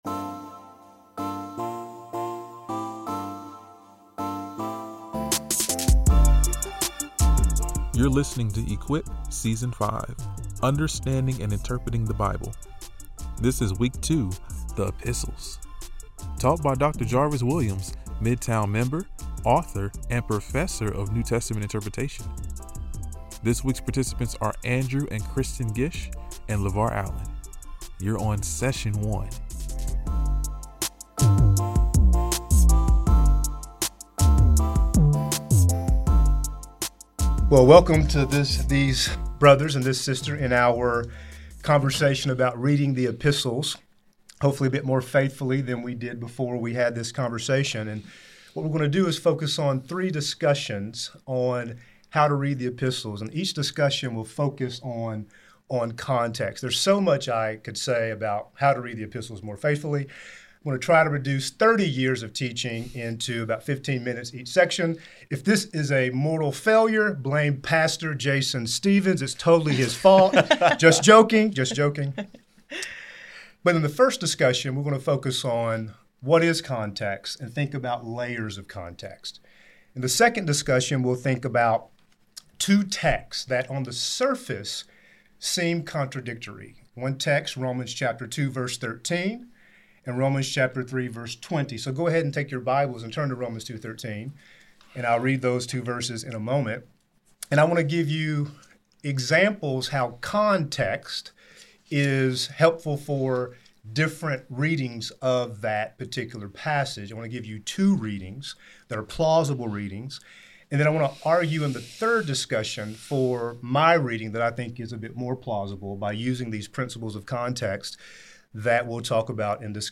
Sermons by Sojourn Midtown in Louisville, KY.